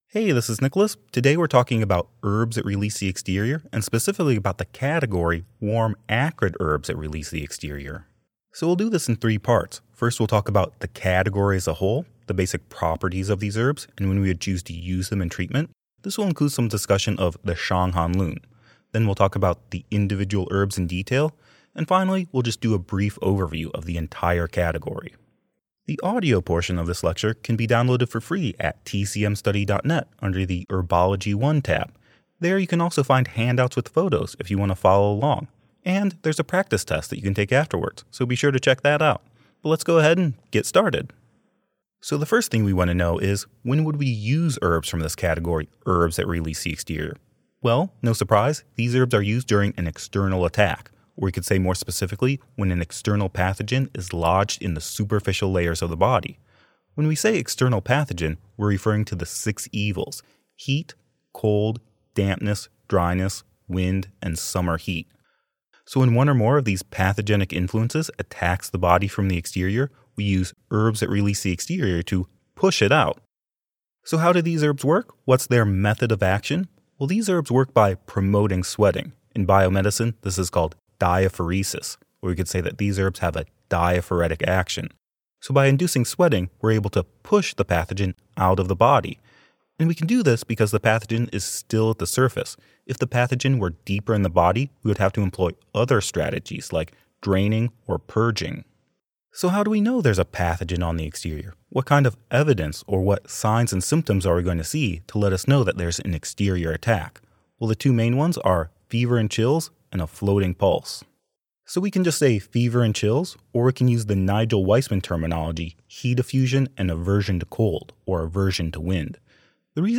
Video lecture covering Warm Acrid Herbs that Release the Exterior. Including what it means to have an exterior attack of wind-cold, Shang Han Lun theory, and the functions of each herb.